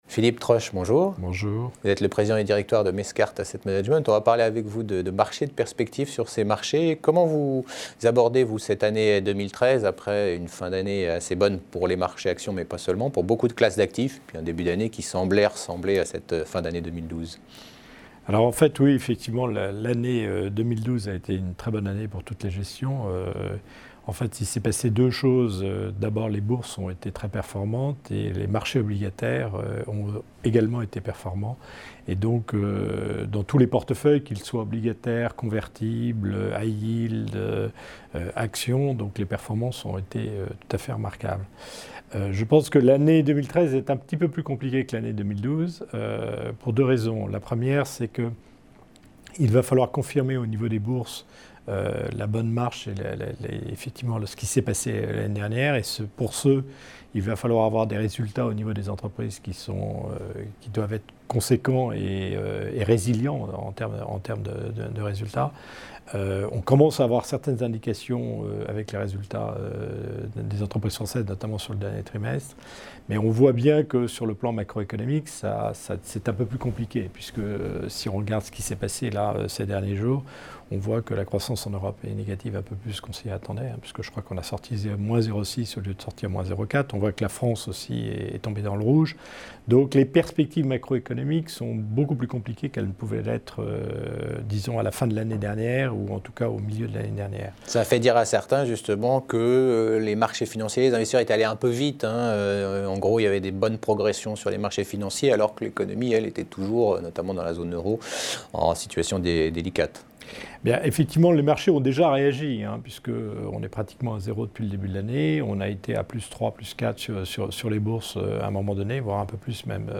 Actualités - Marchés : stratégie et perspectives d'investissement